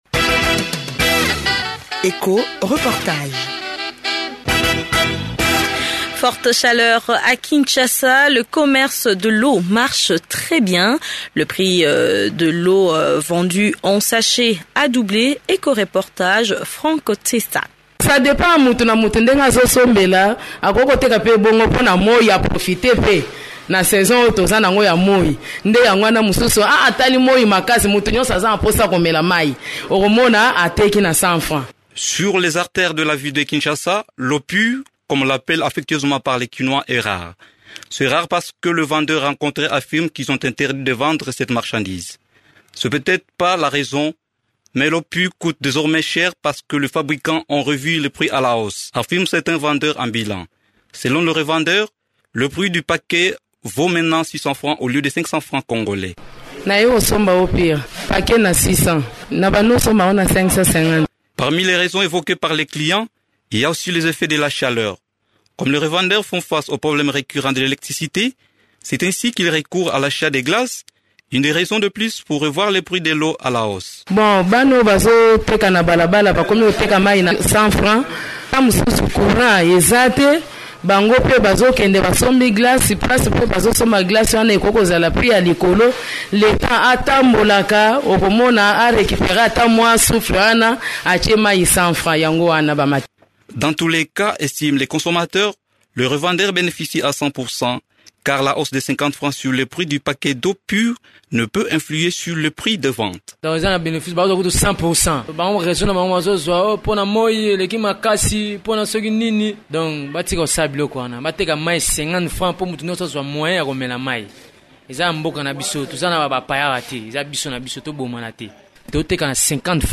Parmi les revendeurs, certains sont mécontents parce que les entreprises congolaises profitent de la situation. Écoutez ce reportage de deux minutes: